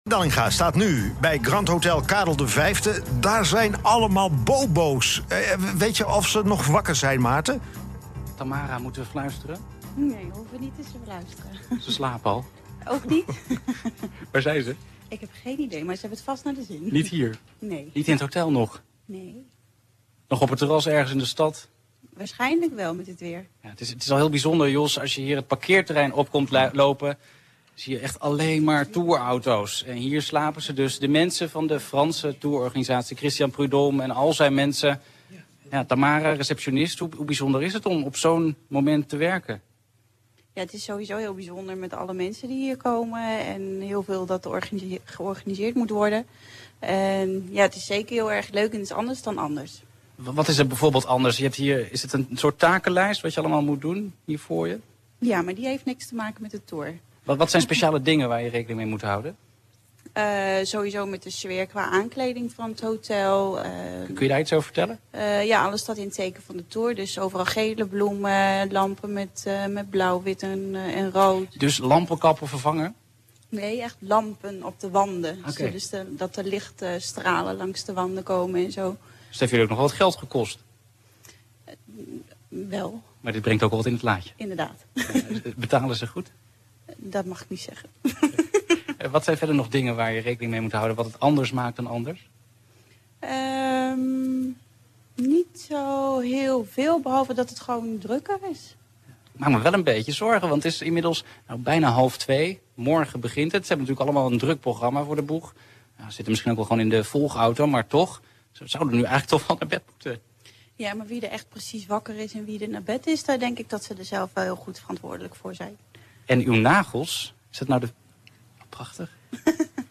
Ik maakte de hele nacht live-reportages voor Radio M Utrecht.